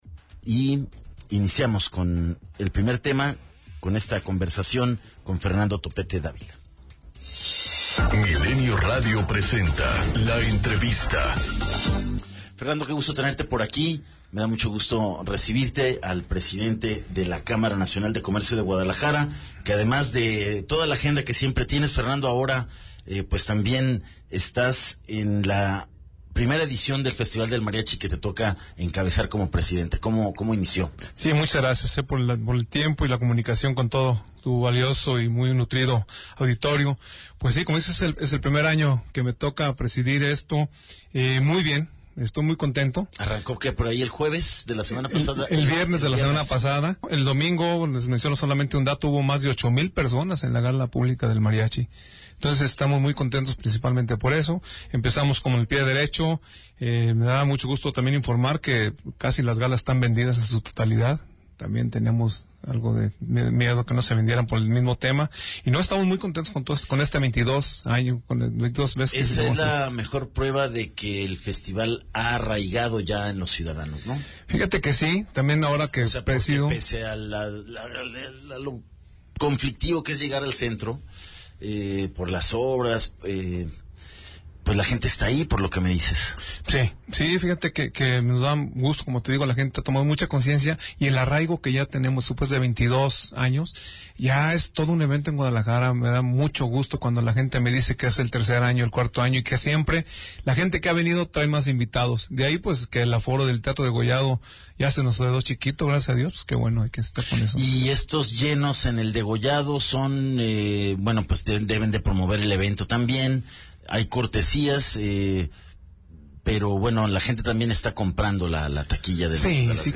ENTREVISTA 010915